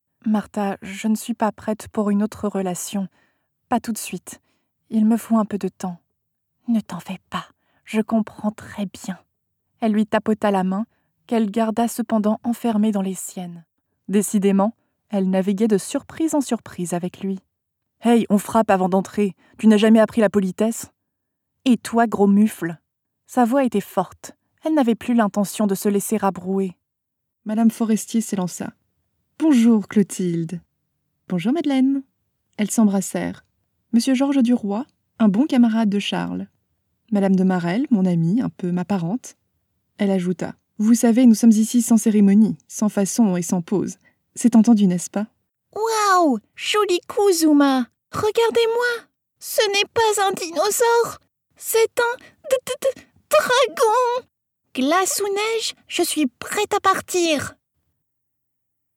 Sprechprobe: Sonstiges (Muttersprache):
I am a French (Paris) and US-English voice actress.